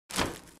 Звуки MacBook, iMac
Звуковые оповещения носимого устройства, синхронизированного с MacOS